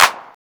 CLAP     6-L.wav